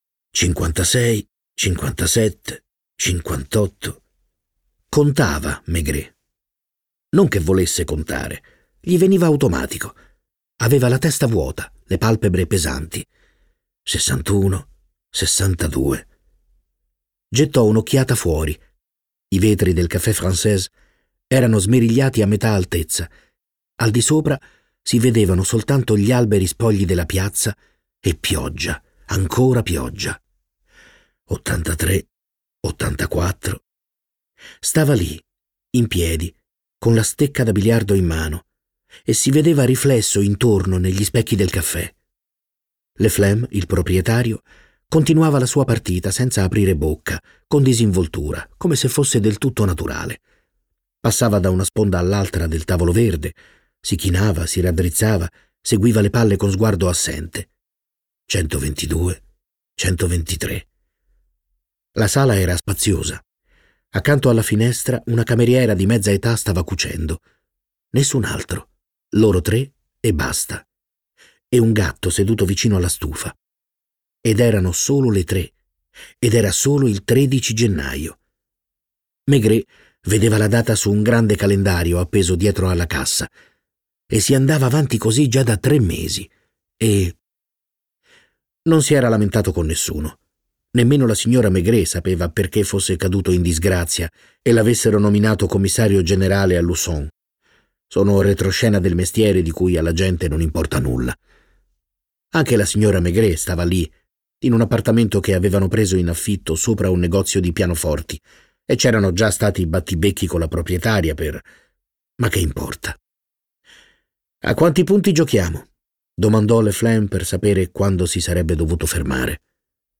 letto da Stefano Fresi
Versione audiolibro integrale